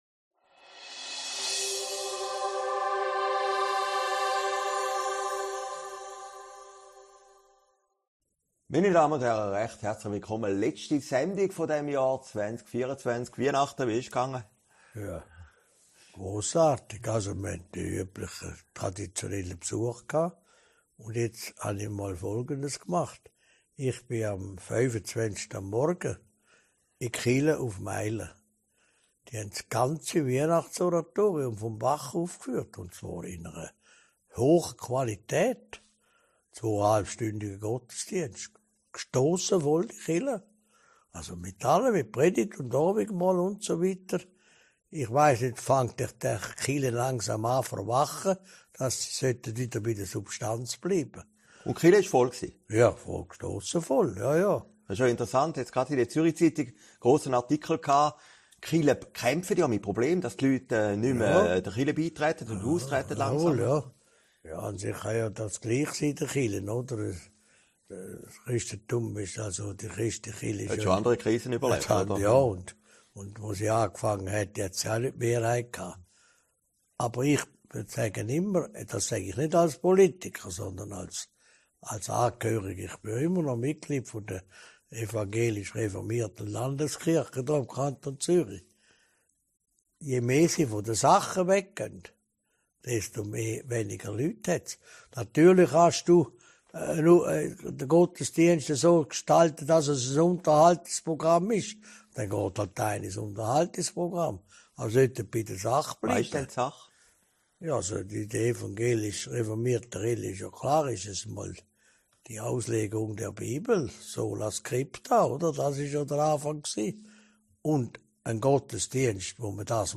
Sendung vom 9. Mai 2045, aufgezeichnet in Herrliberg